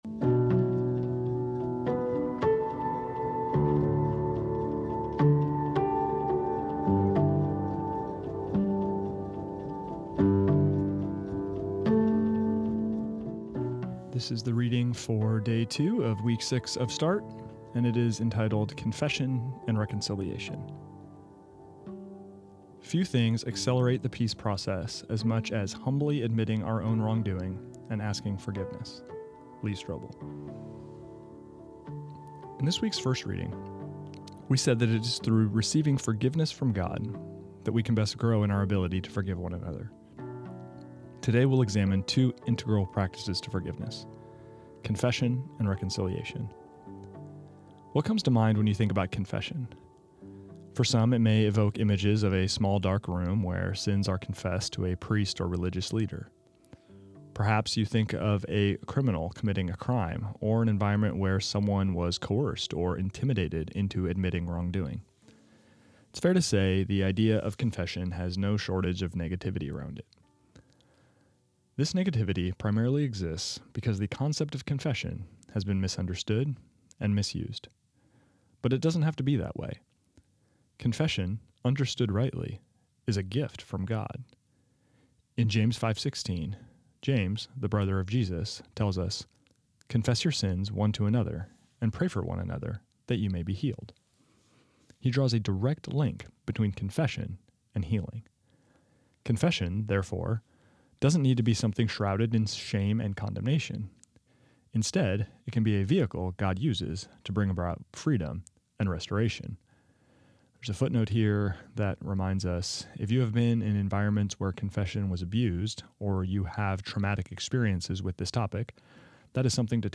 This is the audio recording of the second reading of week eight of Start, entitled Confession and Reconciliation.